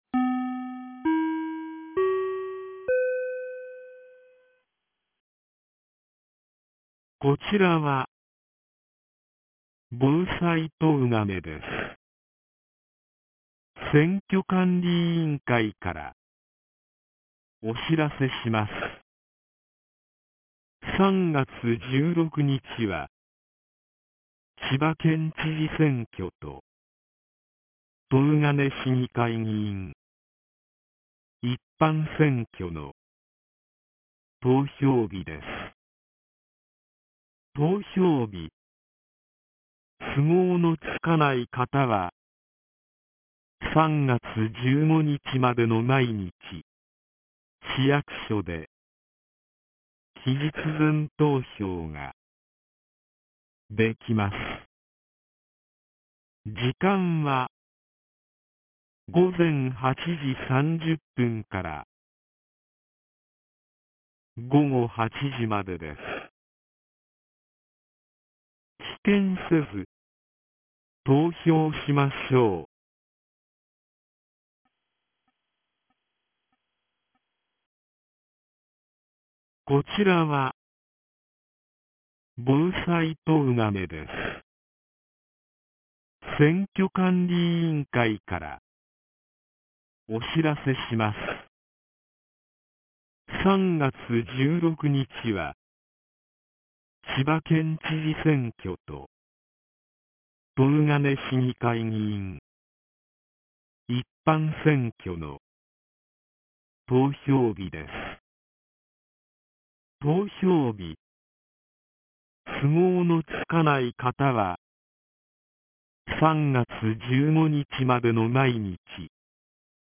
2025年03月10日 14時03分に、東金市より防災行政無線の放送を行いました。